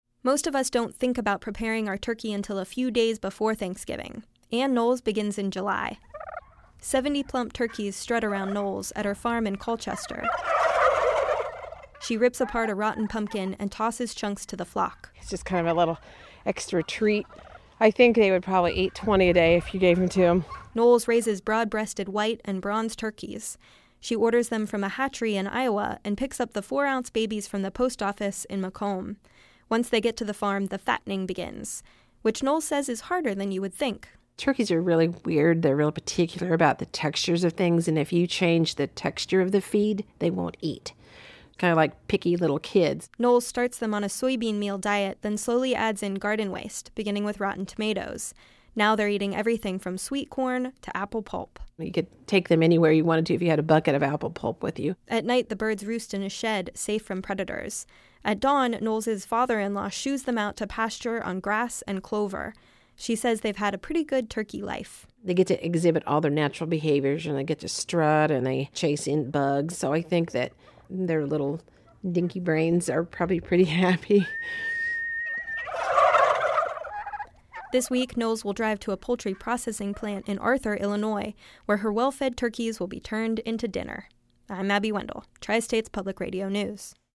The radio story